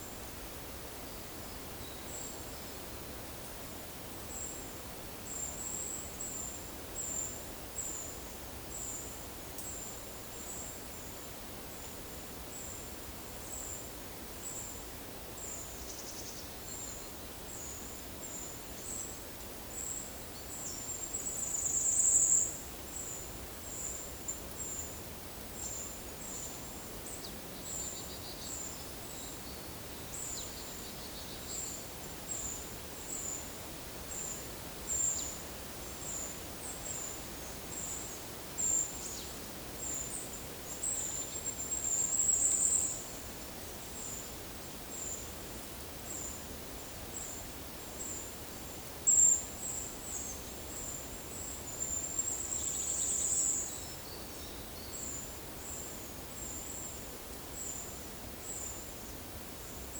PEPR FORESTT - Monitor PAM - Renecofor
Certhia familiaris
Regulus ignicapilla
Certhia brachydactyla
Poecile palustris